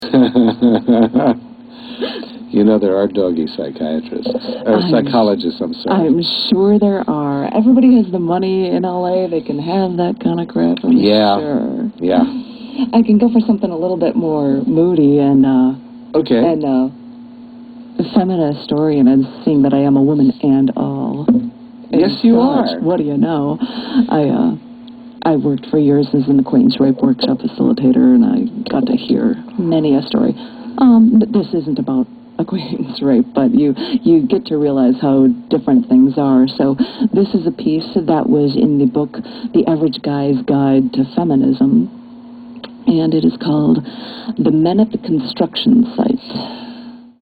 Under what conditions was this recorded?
Interview on the Poetry Radio Circus radio show, WZRD 88.3 FM, 11/07/04